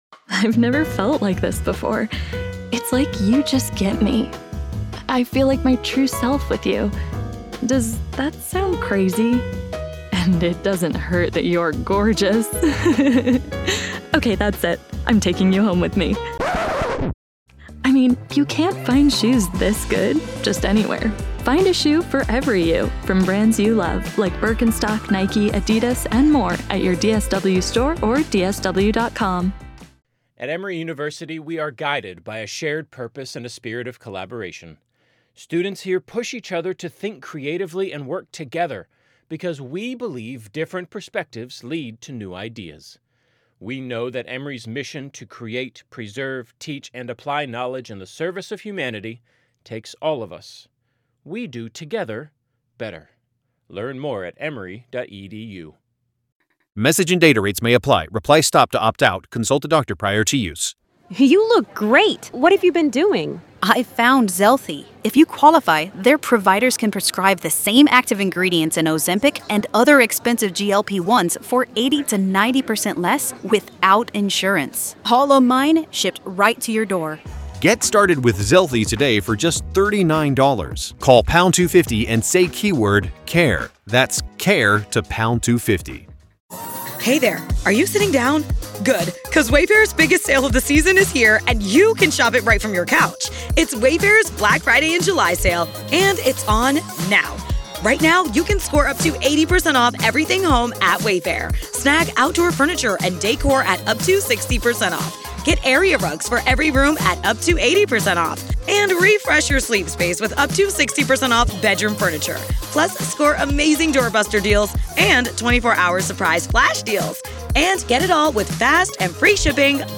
The conversation explores Burke's history of accusations and charges, including civil rights violations and possessing disturbing materials, alongside Heuermann's alleged crimes involving sex workers near Gilgo Beach.